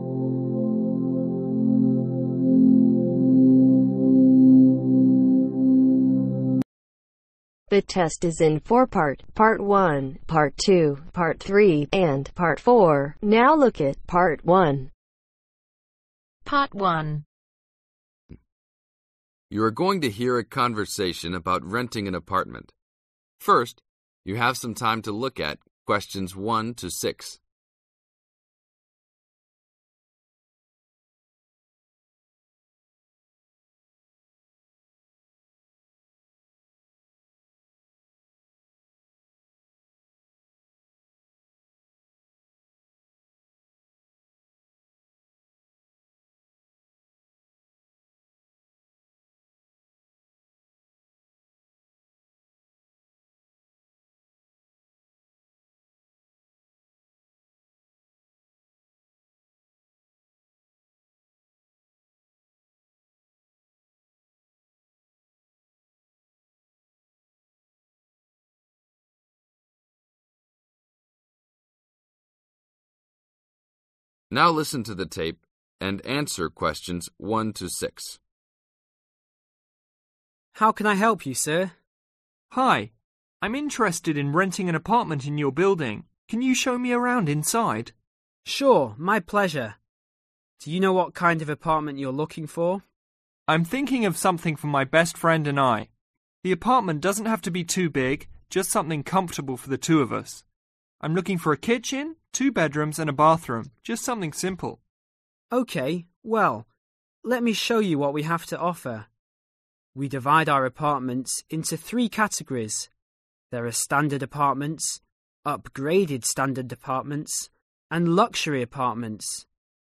Latest listening practice test with answer